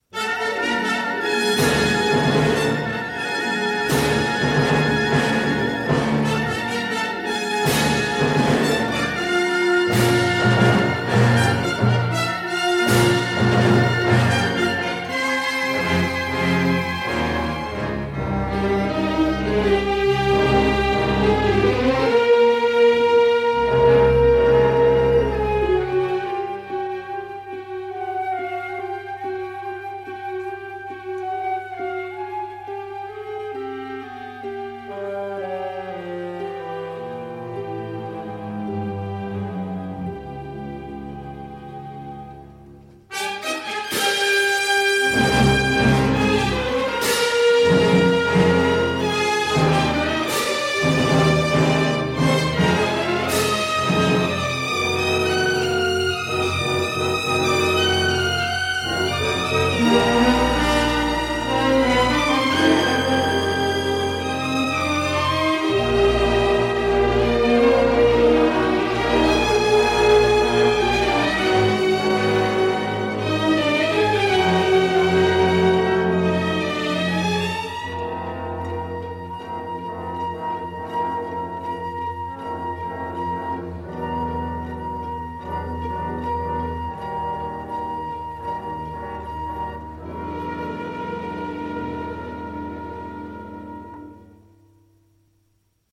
Car l’on y trouve du caractère et de l’invention à revendre.